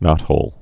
(nŏthōl)